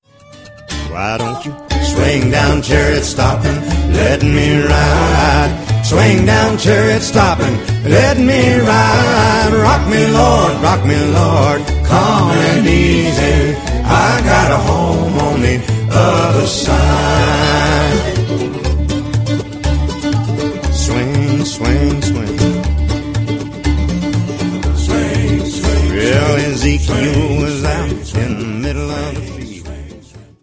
Songs od Faith, Worship and Praise
rein akkustisch
• Sachgebiet: Country